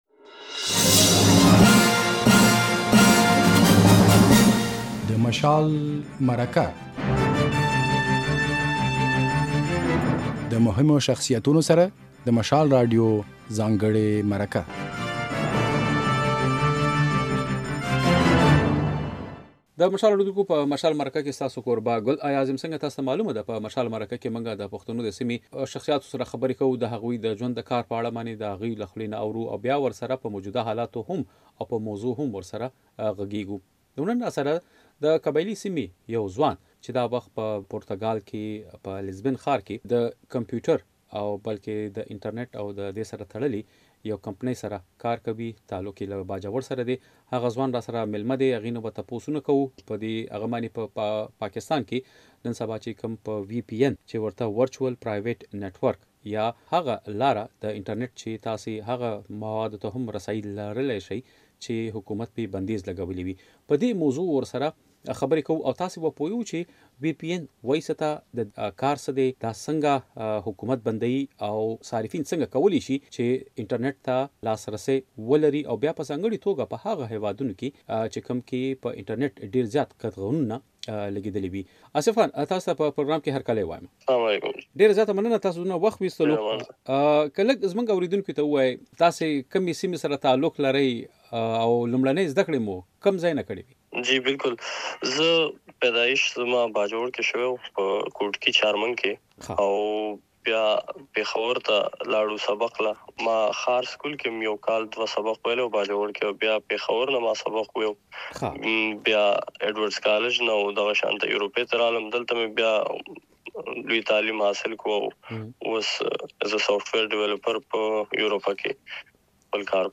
د نوموړي په خبره، اوسنی دور د ټېکنالوژۍ دی چې پکې پر انټرنېټ بندیز لګول یا محدودول د هېواد په ګټه نه تمامېږي. بشپړه مرکه واورئ.